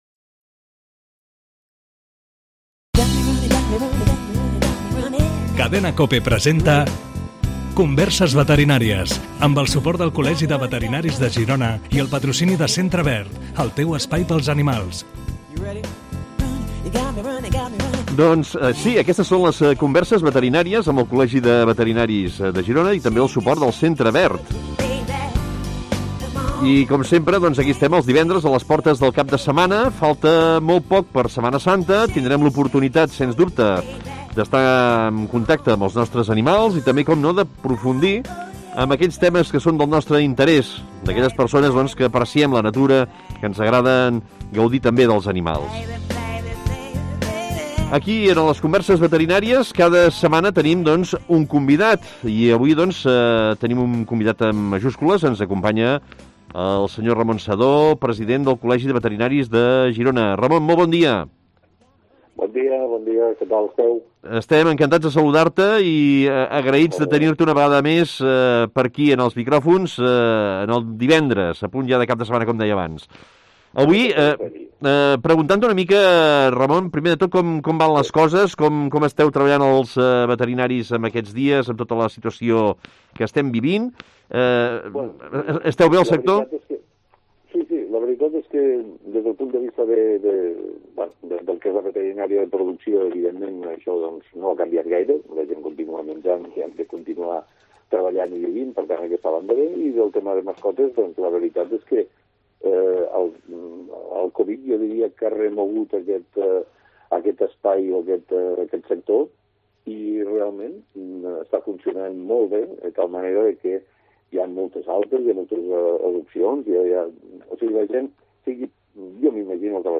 Es contesta per ràdio a les preguntes de propietaris de gossos i gats.